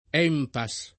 vai all'elenco alfabetico delle voci ingrandisci il carattere 100% rimpicciolisci il carattere stampa invia tramite posta elettronica codividi su Facebook ENPAS [ $ mpa S ] n. pr. m. — sigla di Ente Nazionale di Previdenza e Assistenza per i dipendenti Statali